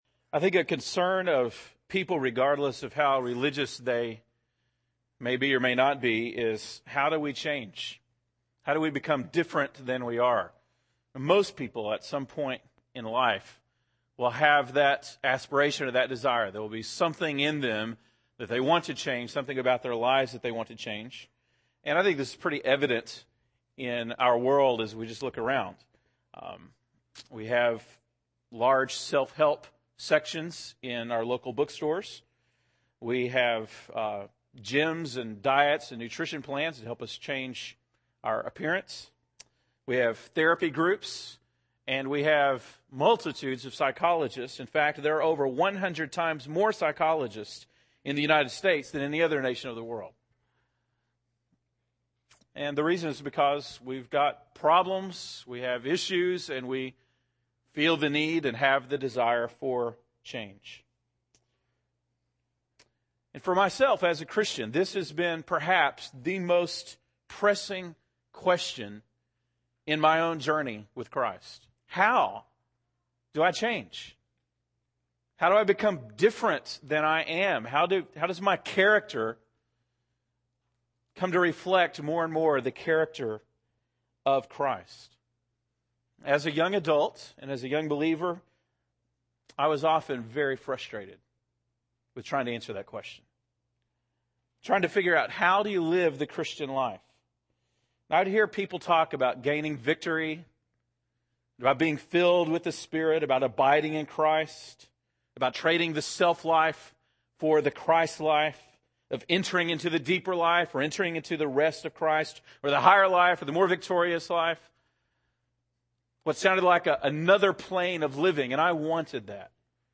October 11, 2009 (Sunday Morning)